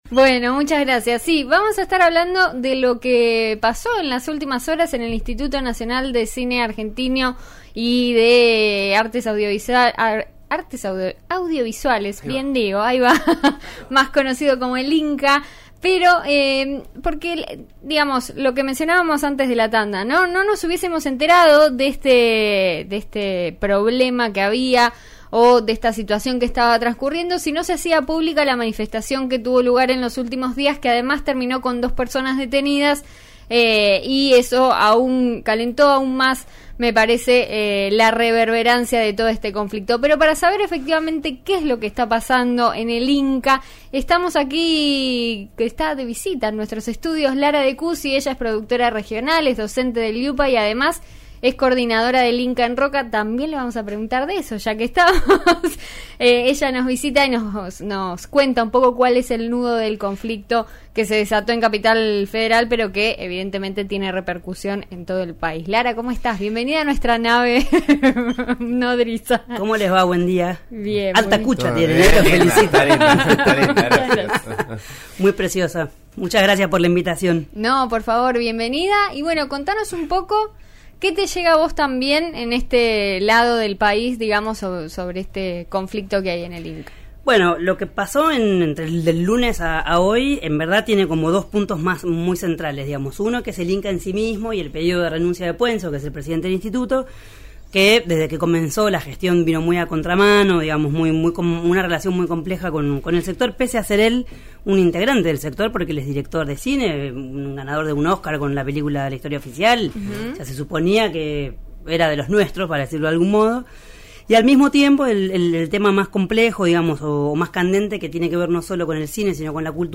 El Intendente de San Carlos de Bariloche pasó por el aire de En Eso Estamos por RN Radio (FM 89.3 en Neuquén) y analizó las repercusiones del fin de semana largo. Además, anunció que en breve se oficializará un programa para alentar al turismo interno.